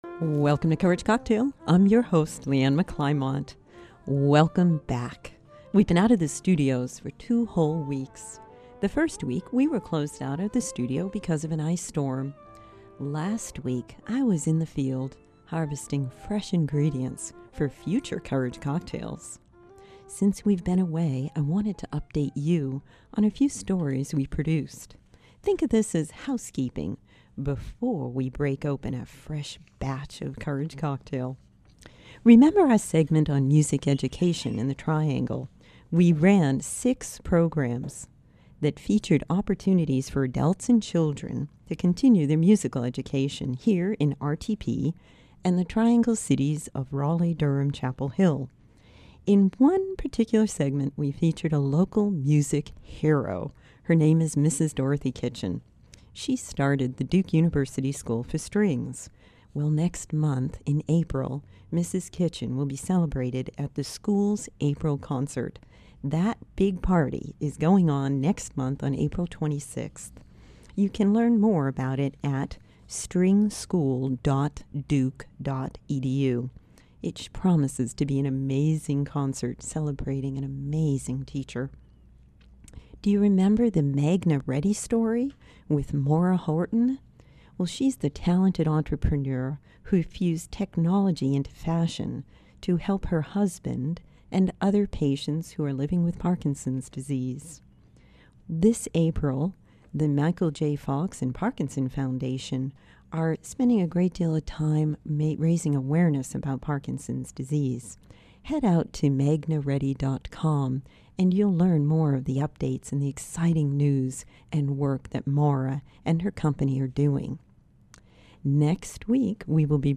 Listen to the WCOM podcast>> The interview starts at 2:44.